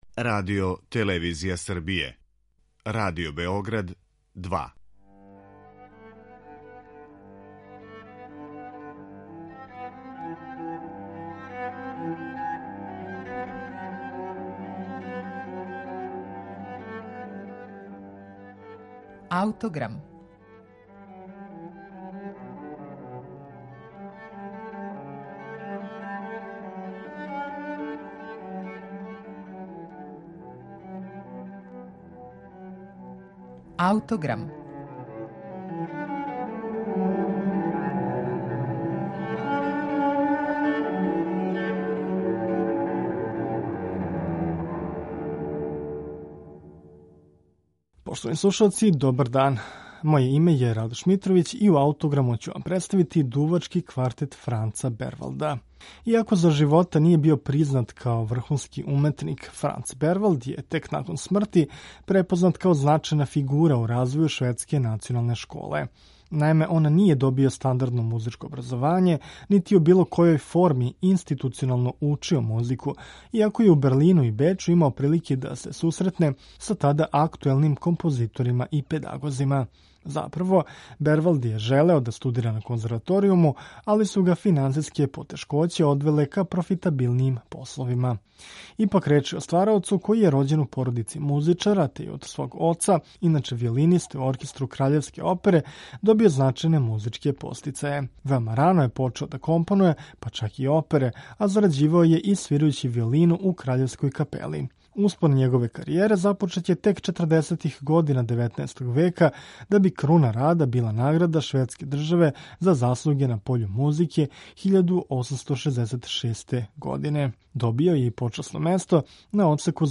Изводи га дувачки квинтет Арион.